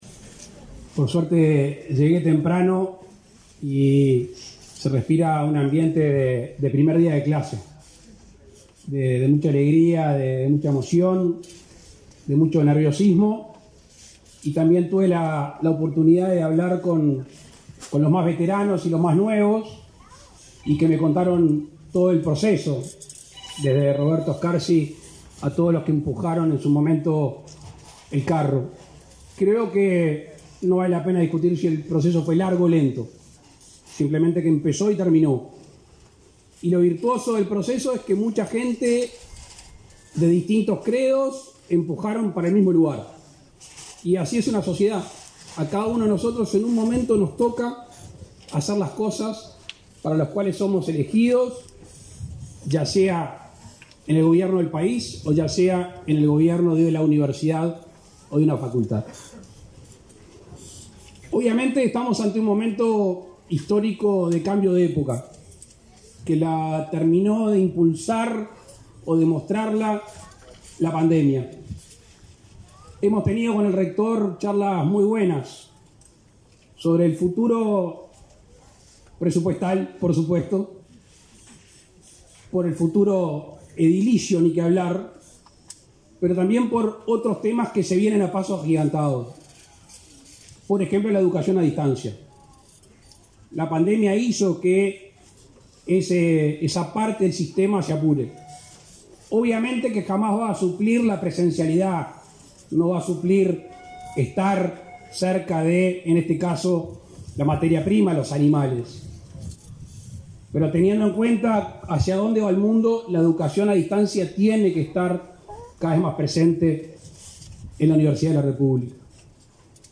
Palabras del presidente de la República, Luis Lacalle Pou
El presidente de la República, Luis Lacalle Pou, participó en la inauguración de la sede de la Facultad de Veterinaria, ubicada en la ruta 8 y Camino